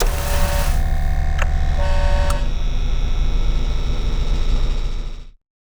shieldson.wav